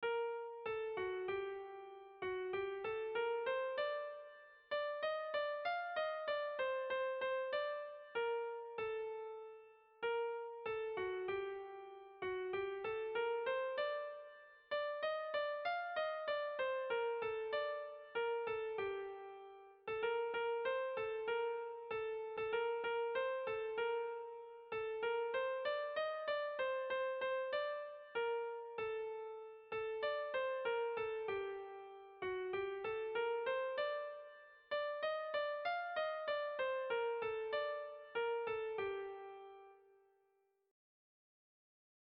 Air de bertsos - Voir fiche   Pour savoir plus sur cette section
Zortziko handia (hg) / Lau puntuko handia (ip)
A1A2BA3